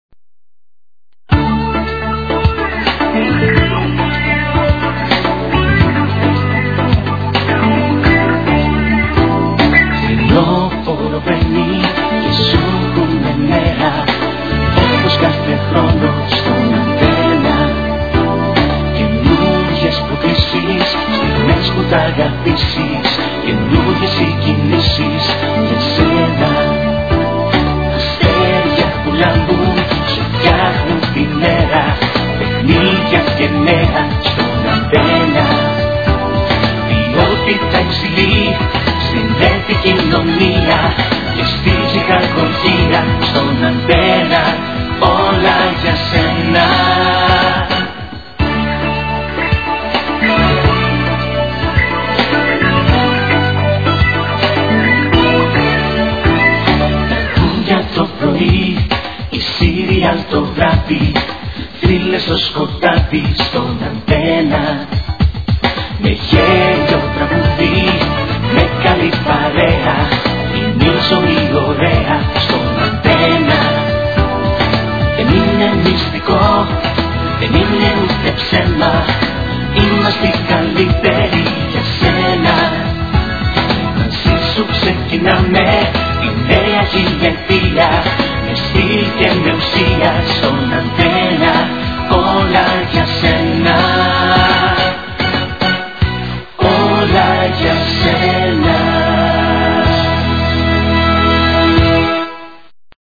this promotional trailer was aired
High quality